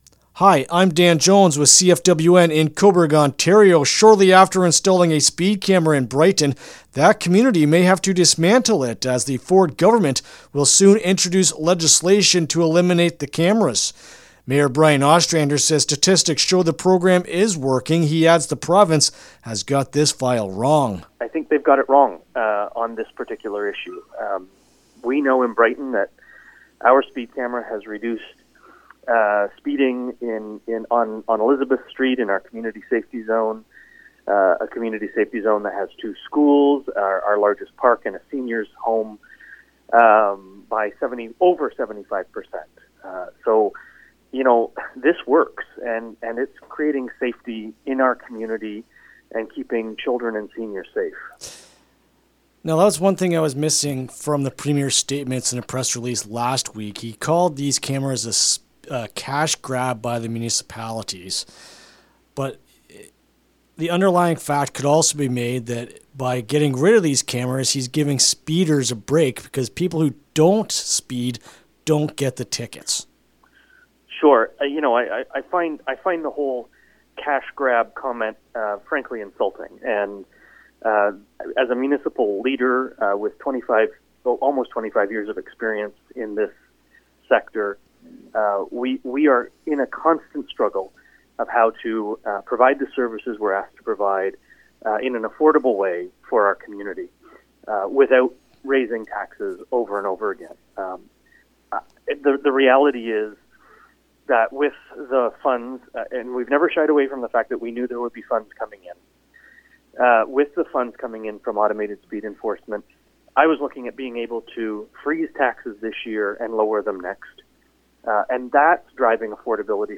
Brighton-Mayor-Ostrander-Speed-Camera-interview-LJI.mp3